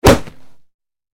Bullet Hits Body With Dry Impact, X3